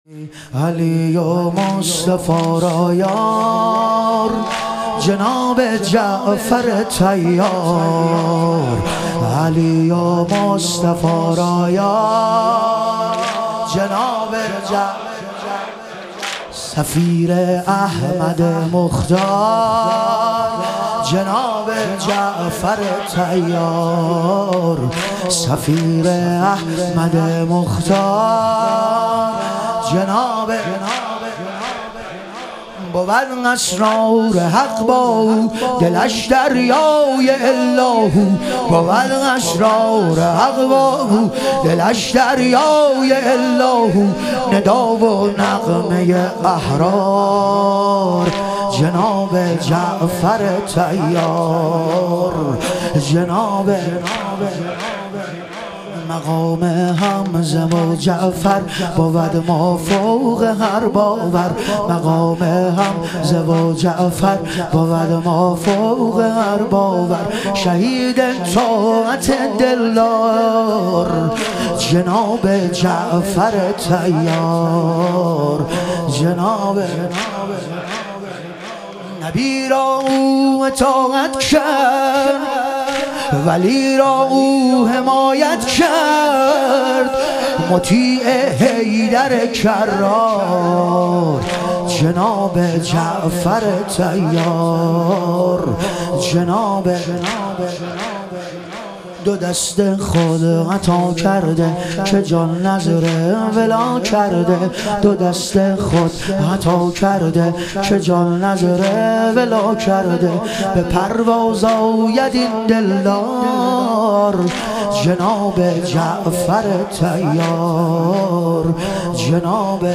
شهادت حضرت جعفرطیار علیه السلام - واحد